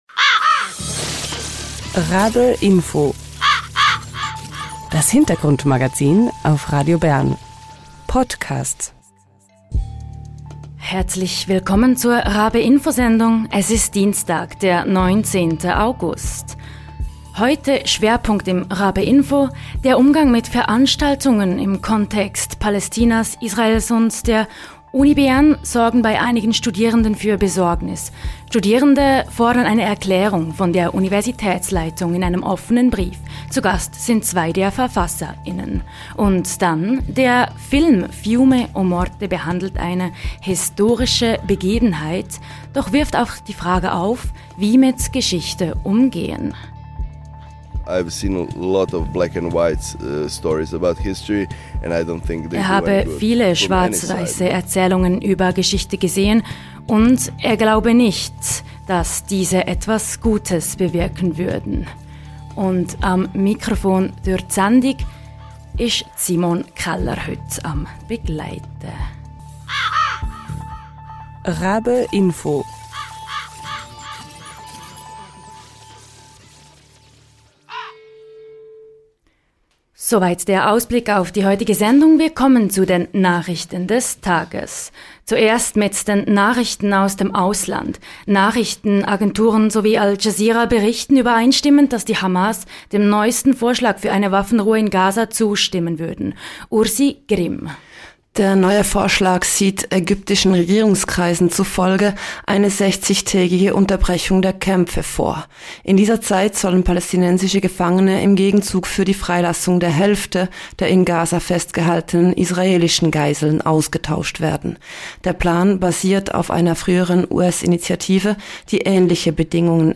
Studierende zeigen sich besorgt: Ein Offener Brief fordert eine Erkärung zum Rückzug der Raumbewilligung der Veranstaltung von Amnesty International mit UN-Sonderberichterstatterin Francesca Albanese am 30. Juni 2025. Zwei der Initiant*innen sind live im RaBe-Info zu Gast.